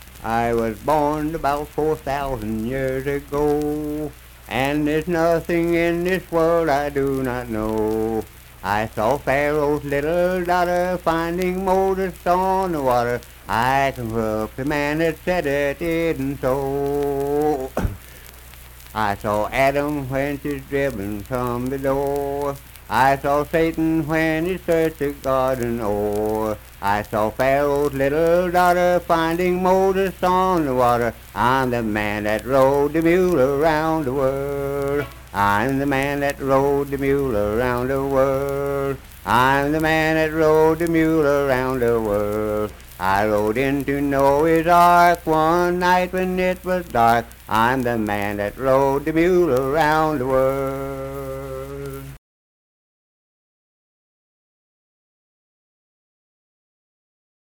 Unaccompanied vocal and banjo music
Hymns and Spiritual Music, Minstrel, Blackface, and African-American Songs
Voice (sung)